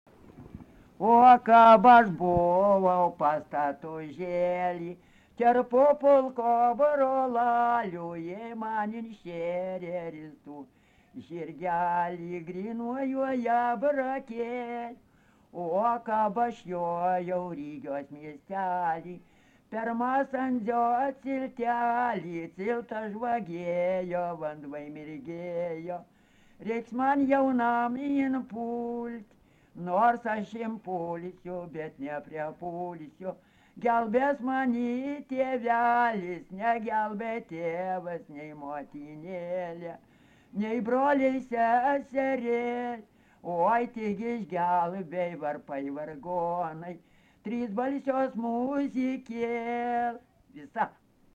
daina, kalendorinių apeigų ir darbo
Erdvinė aprėptis Seirijai
Atlikimo pubūdis vokalinis